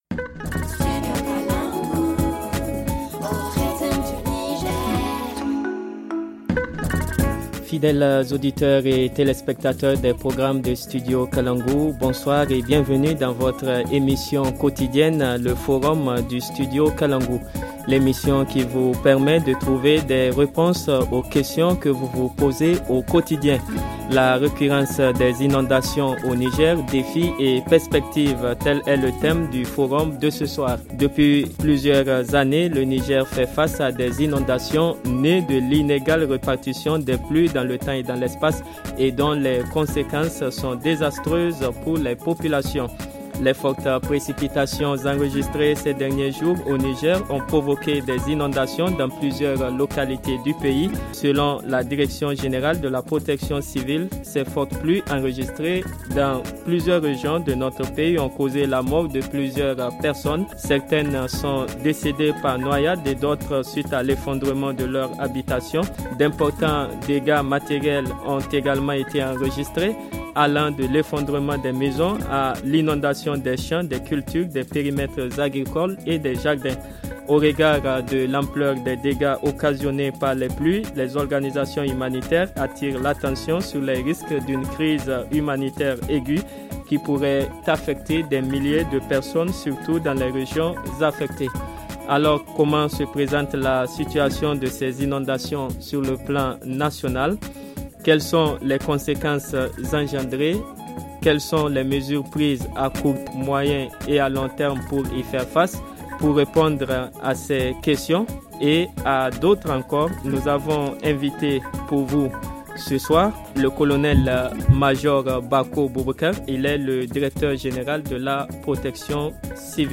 – Colonel-Major Bako Boubacar : Directeur général de la protection civile, – Katiellou Lawan Gaptia : Directeur de la météorologie nationale. FR Le forum en français https